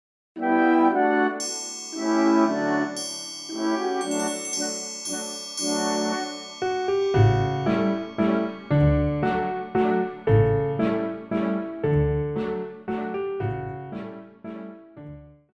Classical
Piano
Orchestra
Solo with accompaniment